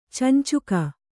♪ cancuka